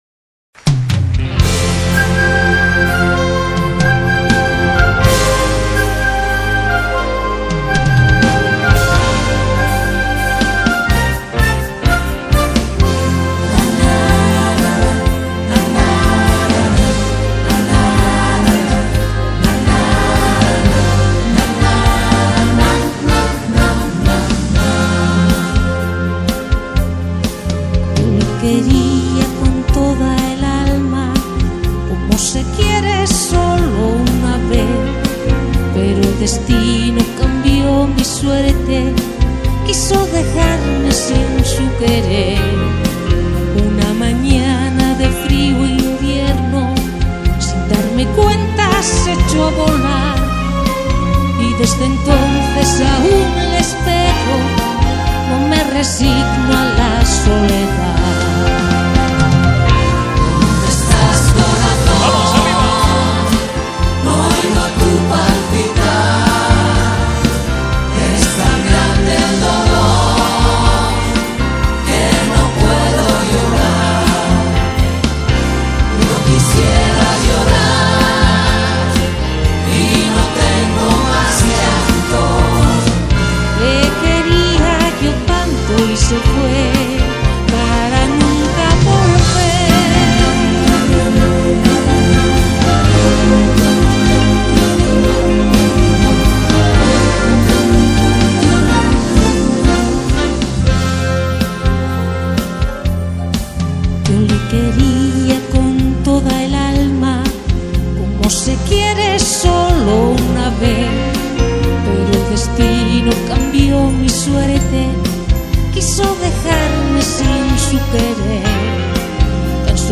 Carpeta: Lentos en español mp3
Sinfonico En Vivo